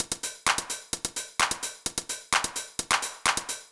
INT Beat - Mix 9.wav